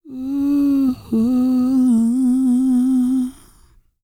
E-CROON P322.wav